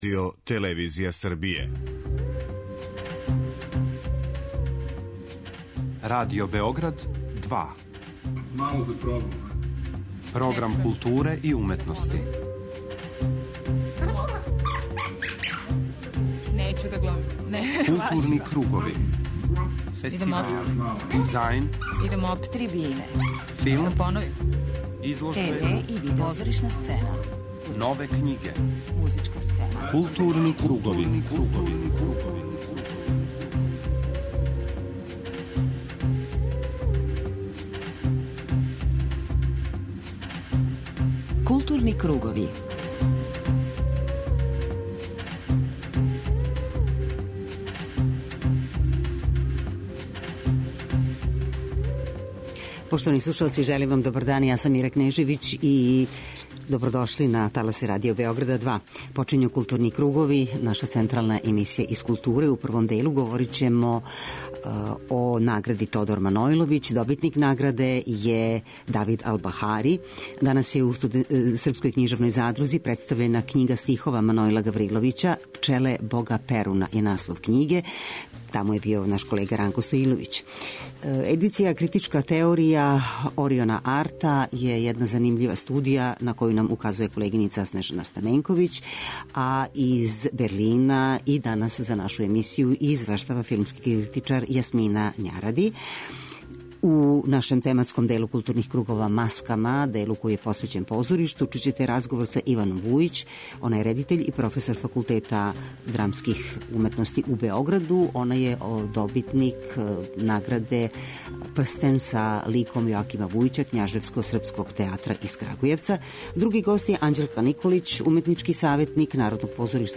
У првом делу емисије информисаћемо вас о културним догађајима, а у 'Маскама', тематском делу емисије посвећеном позоришту, данас имамо две гошће.